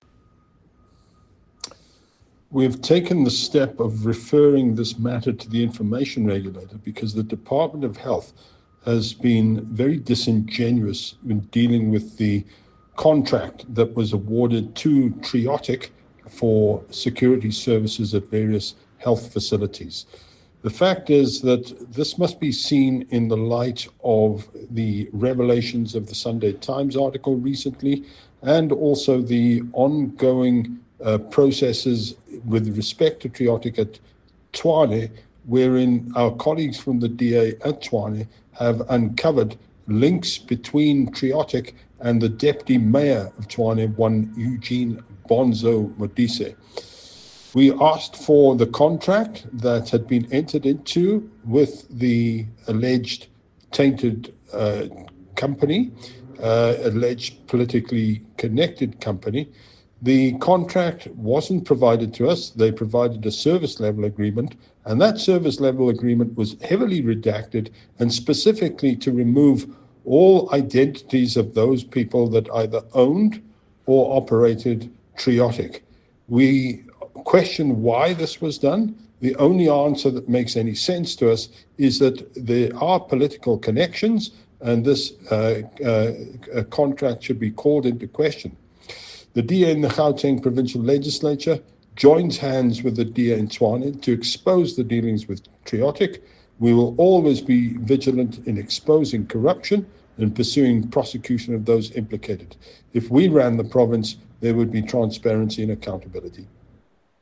soundbite by Mike Moriarty MPL.